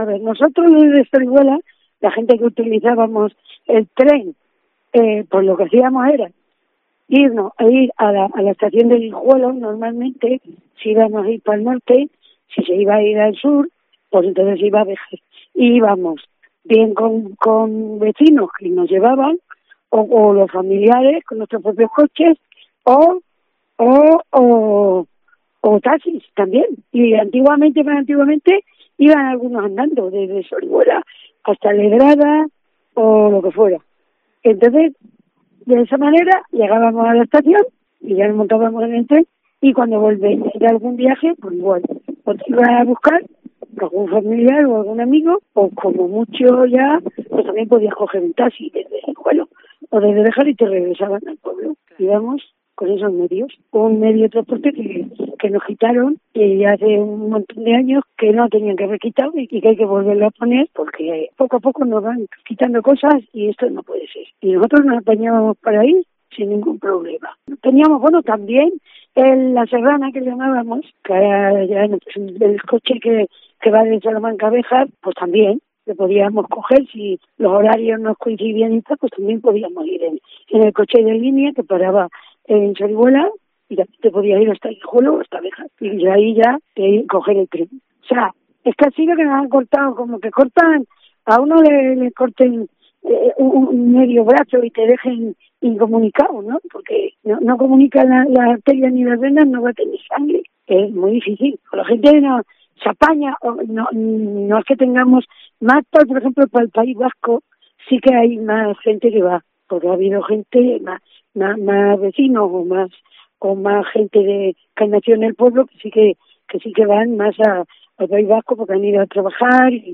Una usuaria del tren de la Ruta de la Plata: "Estás incomunicado, es como si te cortan un brazo"
COPE Salamanca recoge el testimonio